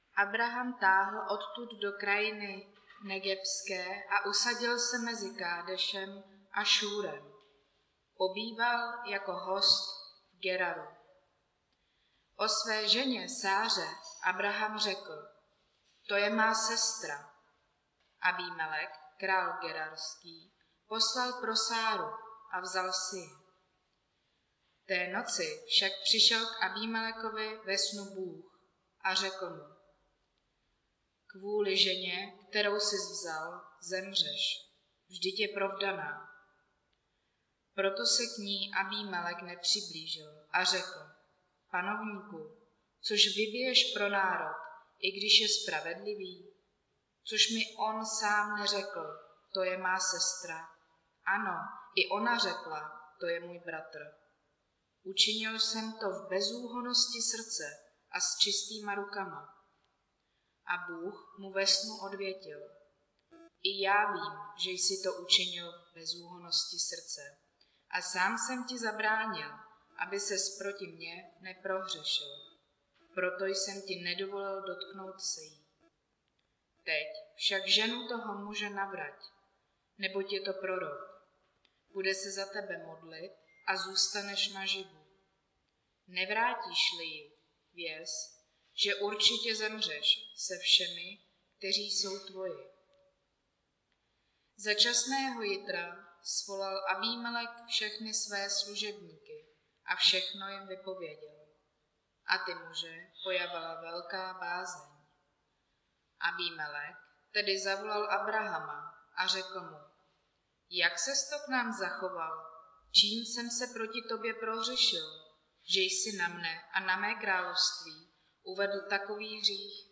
Nedělní kázání – 20.11.2022 Abrahamova chyba